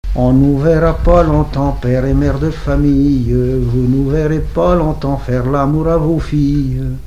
refrain de conscrits
Pièce musicale inédite